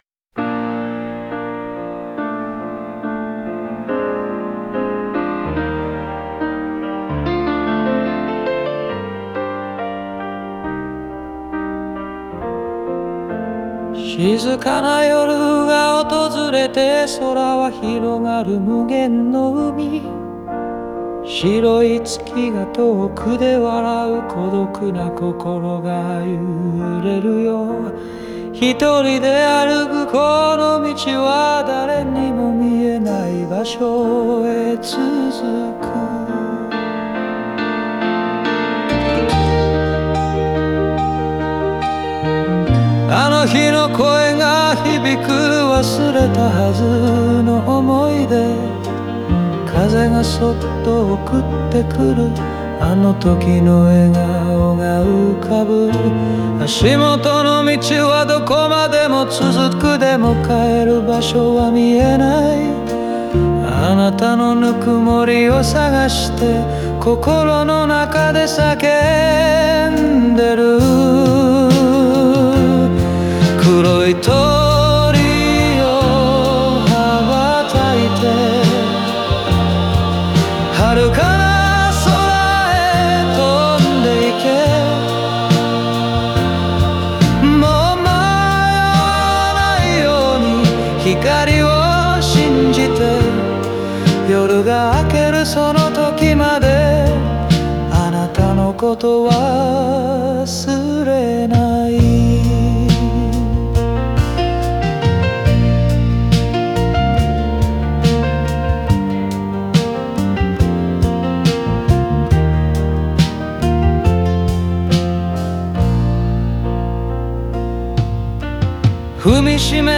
ギターのアルペジオやソロを通じて、感情の起伏が穏やかに表現され、温かみのあるメロディが歌詞に深みを与えています。